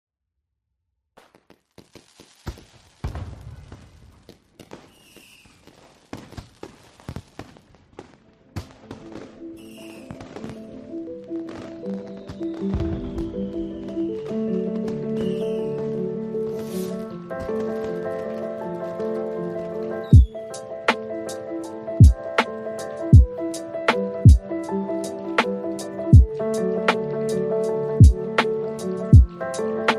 # lofi # hiphop # chill # loop